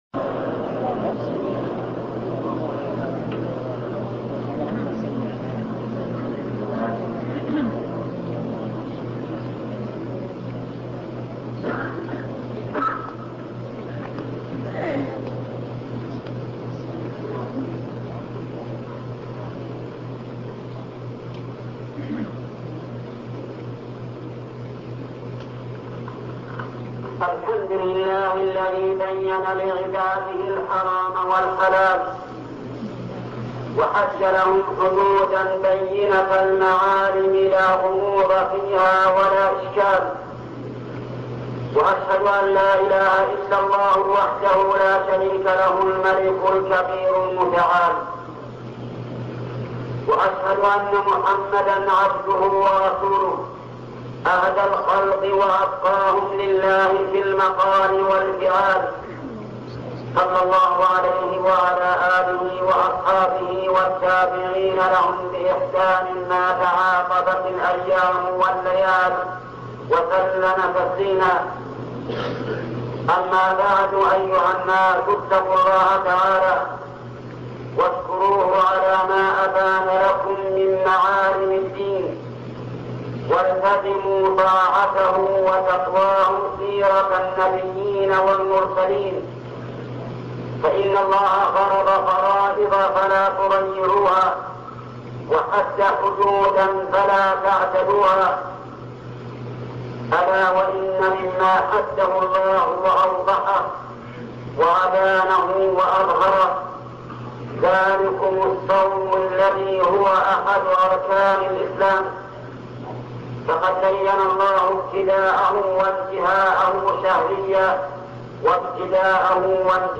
الشيخ محمد بن صالح العثيمين خطب الجمعة